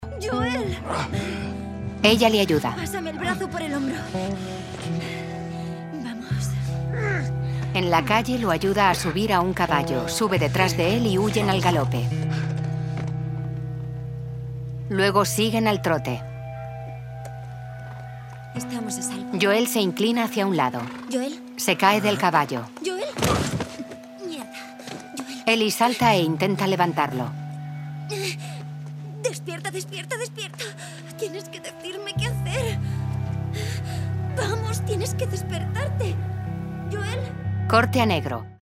AUDIODEAUDIODESCRIPCINENTHELASTOFUSPARTE1.mp3